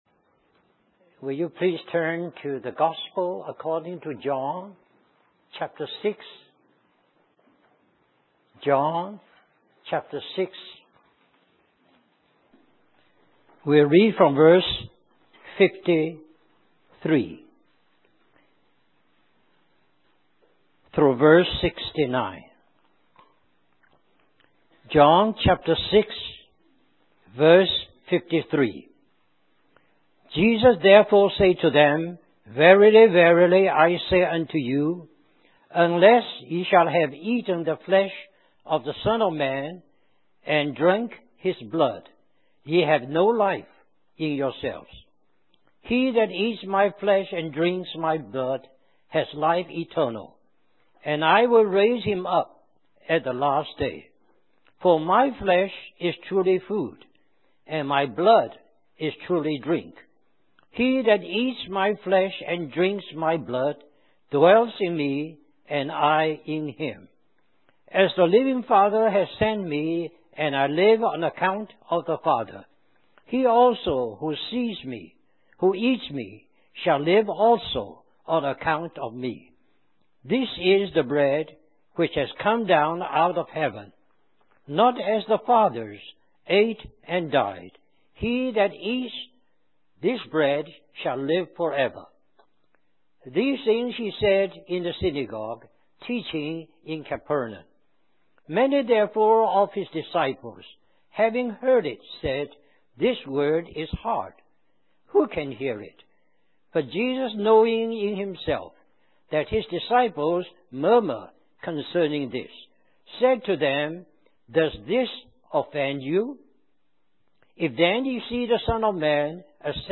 In this sermon, the preacher discusses the story of Simon Peter and the miraculous feeding of the 5,000.